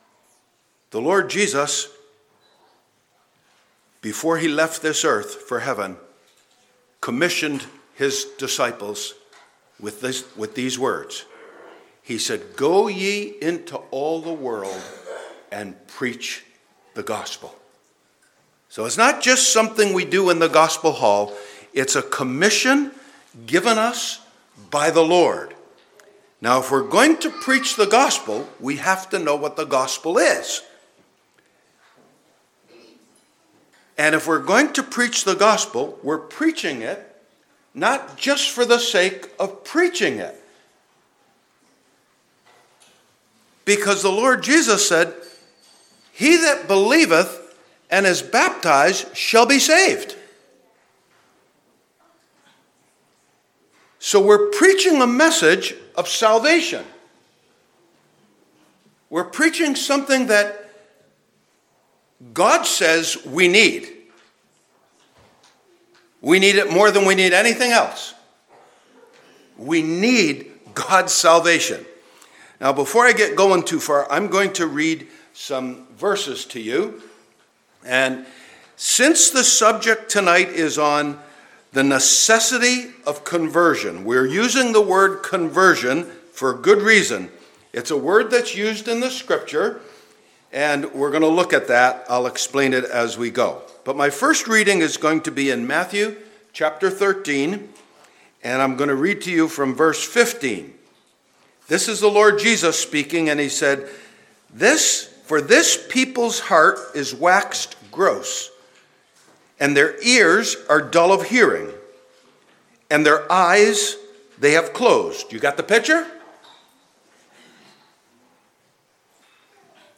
(Recorded in O'Leary Gospel Hall, PEI, Canada)
Individual Sermons on Evangelism